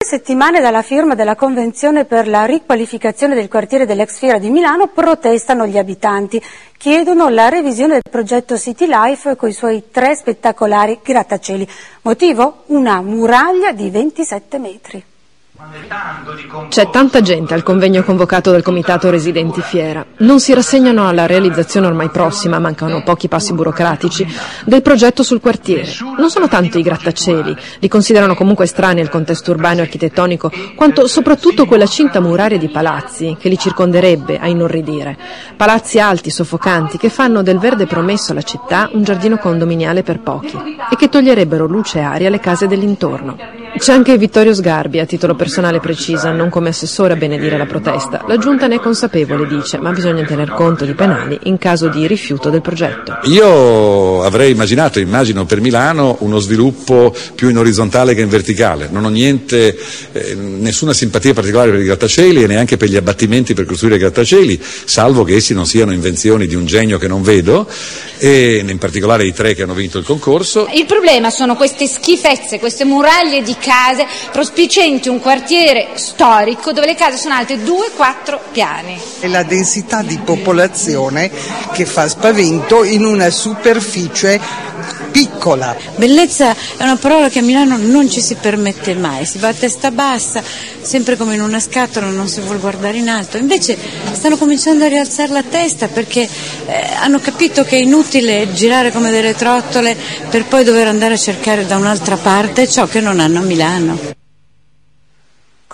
l'audio del servizio andato in onda il 1 dicembre sul Tg Regionale.